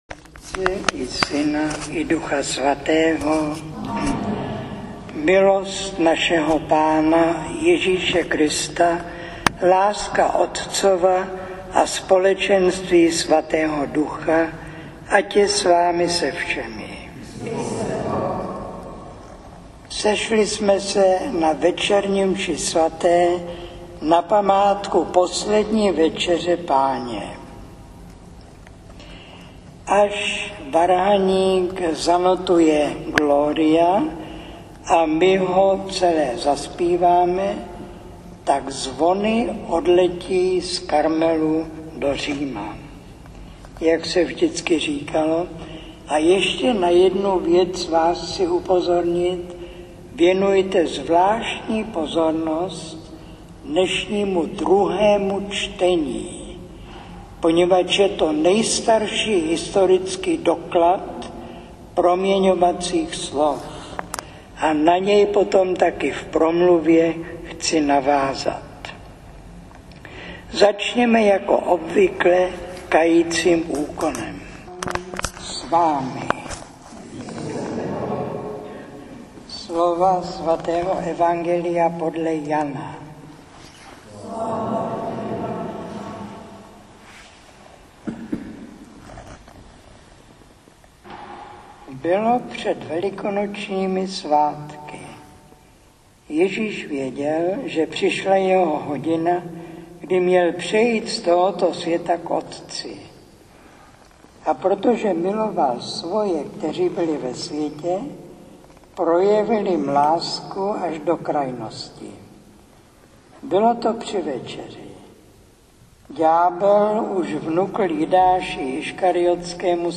Přehrávání + ukládání: Kázání 75 20 MB 192 kb/s mp3 Kázání 75 6.7 MB 64 kb/s mp3 Přehrávání (streaming): není k dispozici Ukládání: není k dispozici Kázání 76 (12 min.)